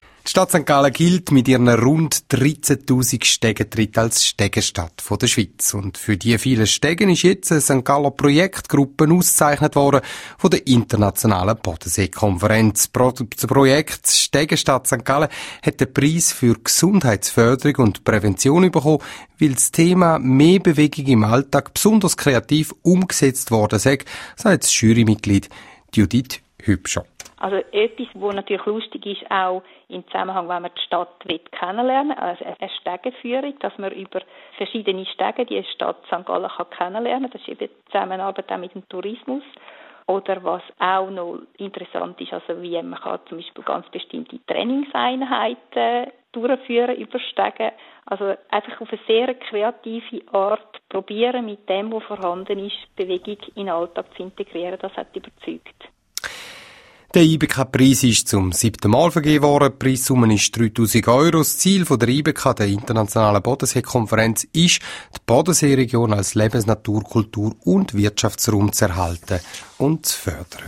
Radiobeitrag)